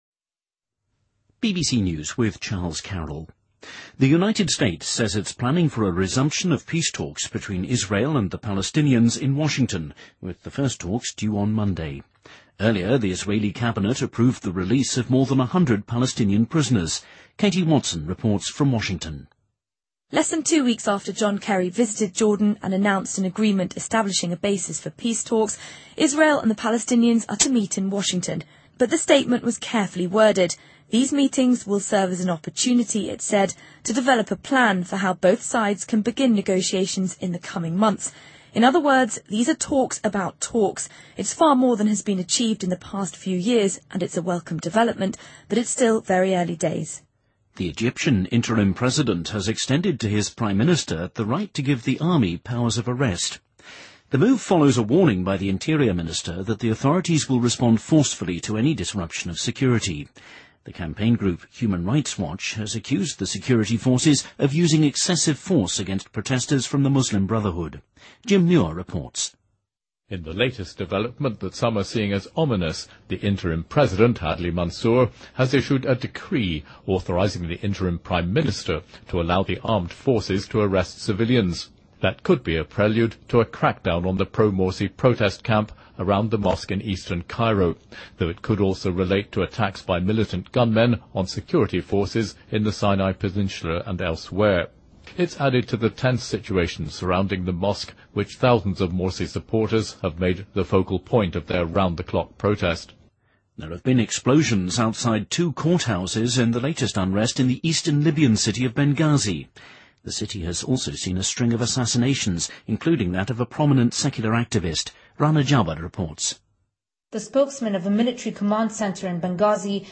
BBC news,电影《音乐之声》中出现的戏装在好莱坞拍卖